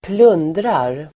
Uttal: [²pl'un:drar]